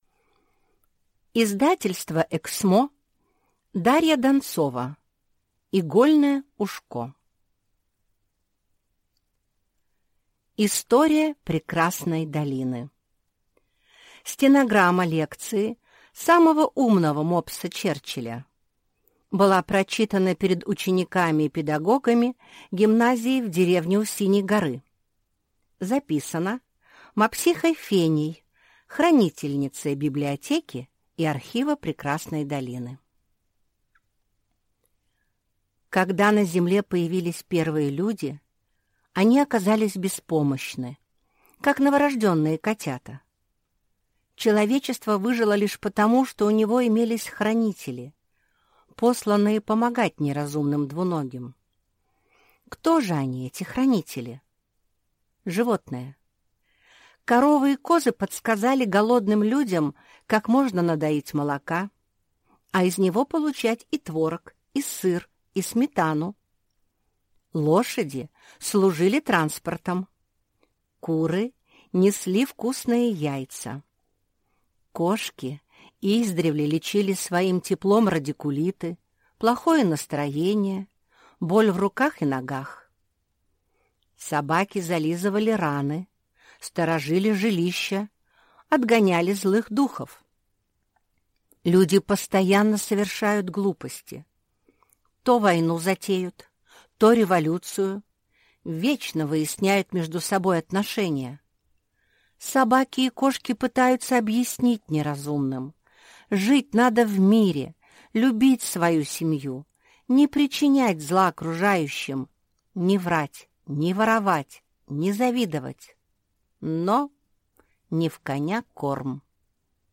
Аудиокнига Игольное ушко | Библиотека аудиокниг